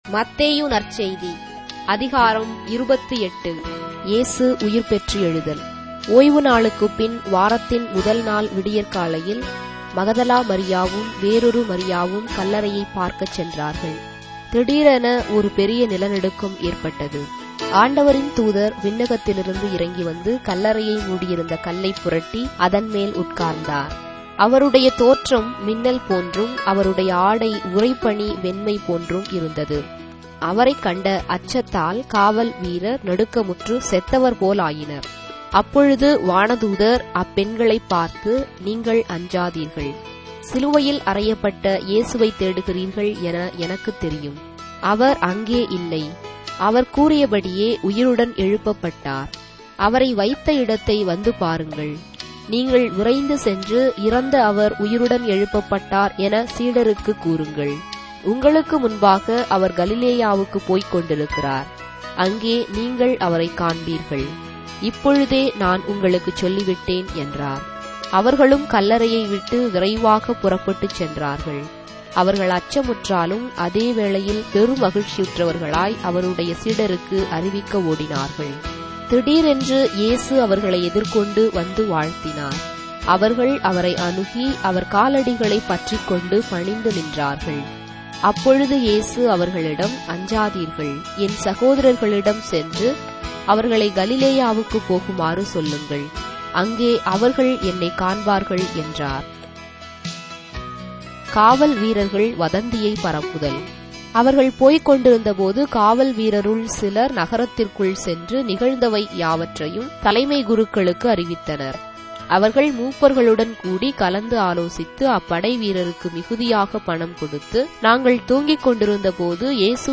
Tamil Audio Bible - Matthew 25 in Ecta bible version